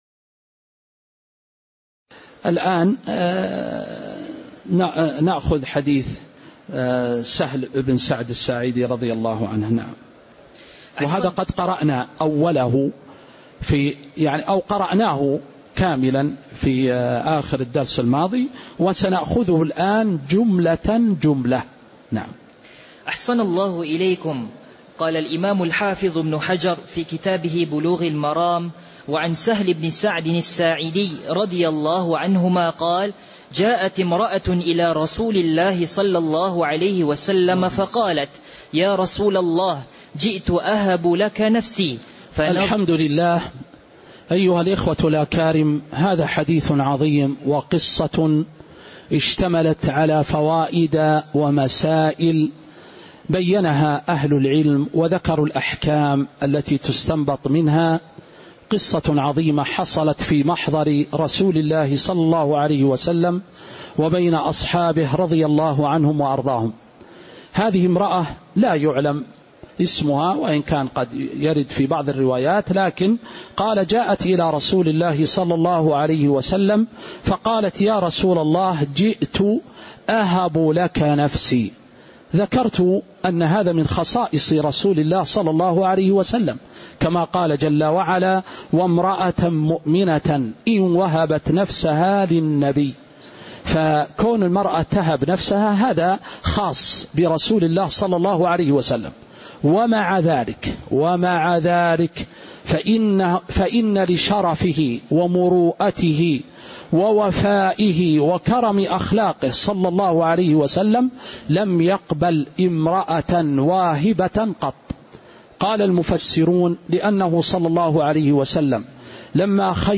تاريخ النشر ١٦ شعبان ١٤٤٦ هـ المكان: المسجد النبوي الشيخ